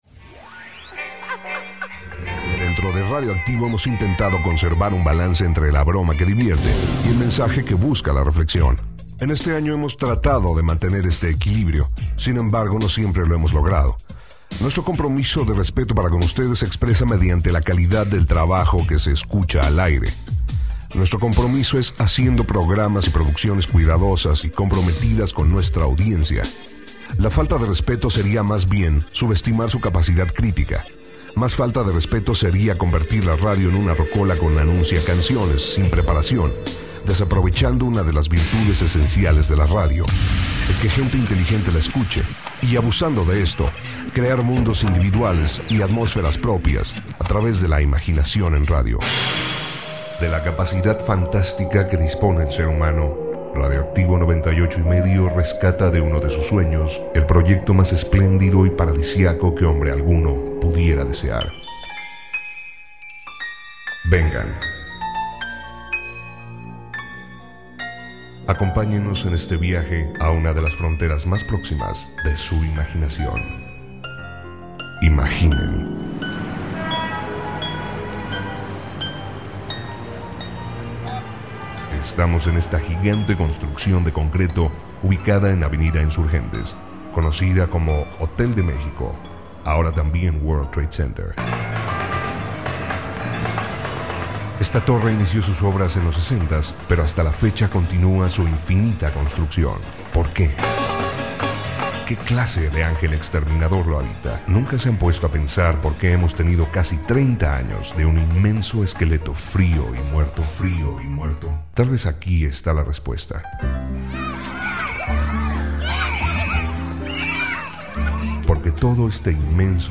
De Radioactivo 98.5 rescatamos un bello ejemplo de lo que debería ser la radio y la intención de crear Arte con el lenguaje radiofónico